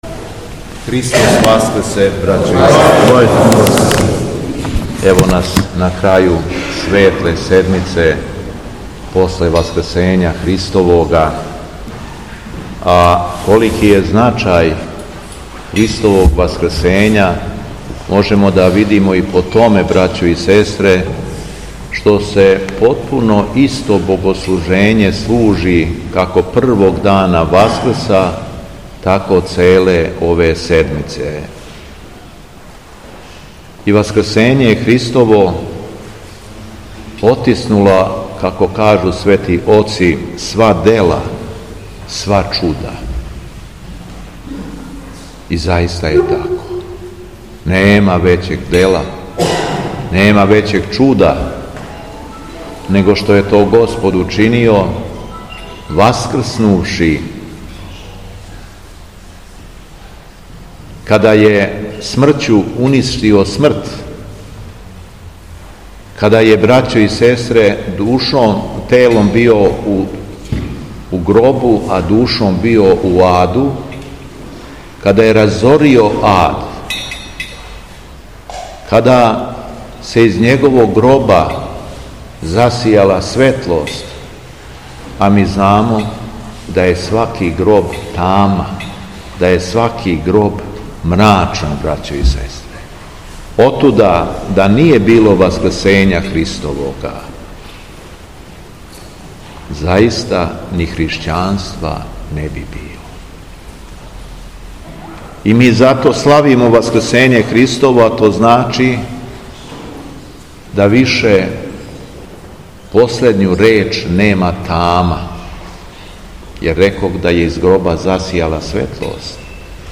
У суботу Светле седмице, 25. априла 2025. године, када наша Света Црква прославља и празнује Светог свештеномученика Артемона Лаодикијског, Његово Високопреосвештенство Митрополит шумадијски Г. Јован служио је Свету Архијерејску Литургију у храму Благовести у Рајковцу.
Беседа Његовог Високопреосвештенства Митрополита шумадијског г. Јована